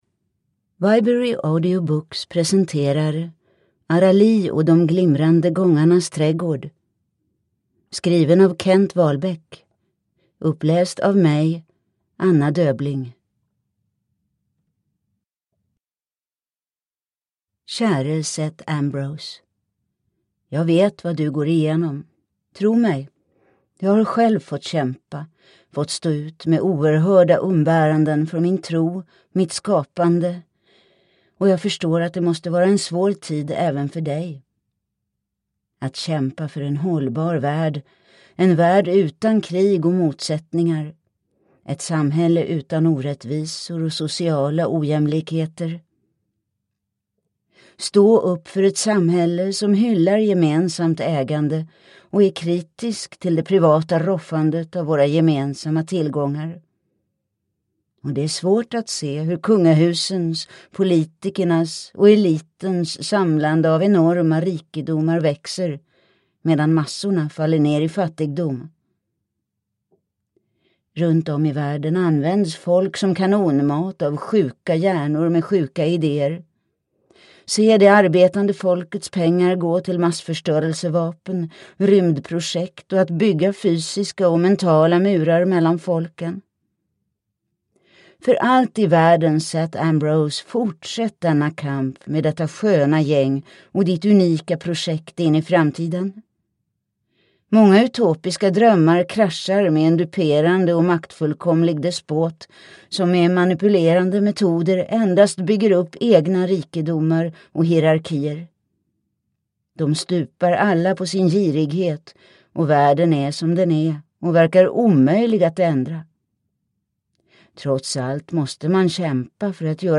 Arali (ljudbok) av Kent Wahlbeck